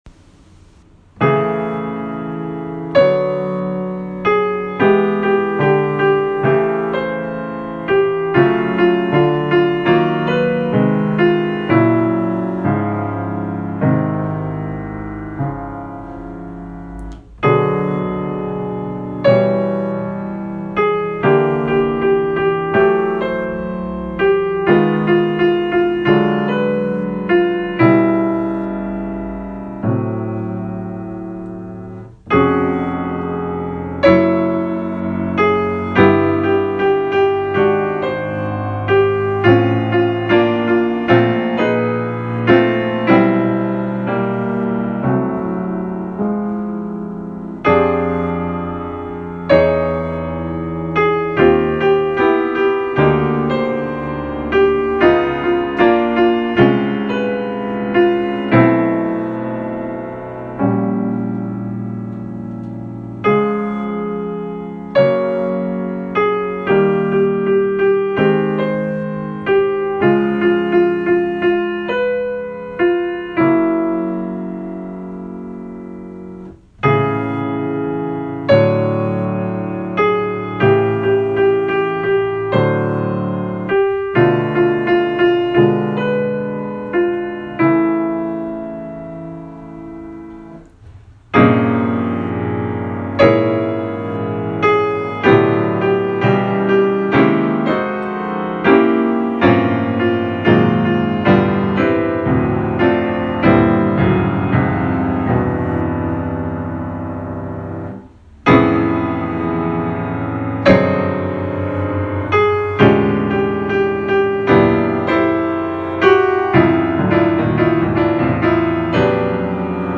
Like Bartók in Mikrokosmos, I gave myself little equations to follow, in this case for making progressively more outrageous chorale harmonizations of Kern’s opening phrase.
The final diabolical harmonization made me think of Steve Coleman.